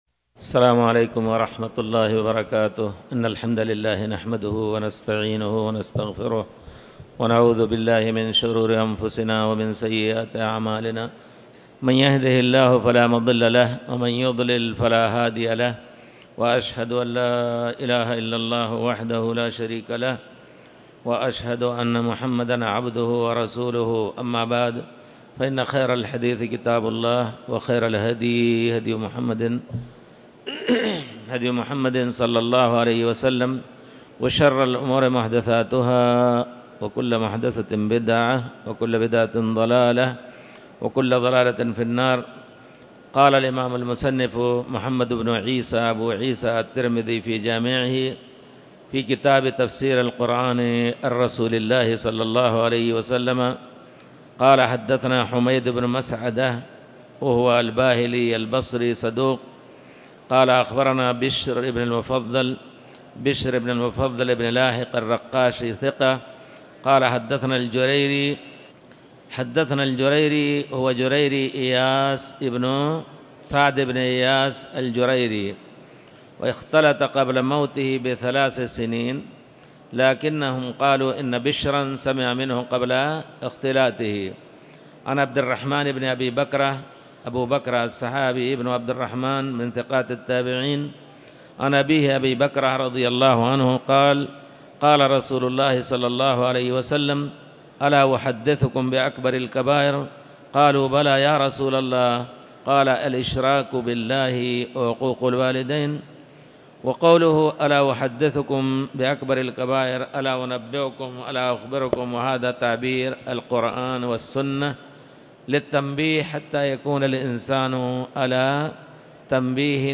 تاريخ النشر ١٩ محرم ١٤٤٠ هـ المكان: المسجد الحرام الشيخ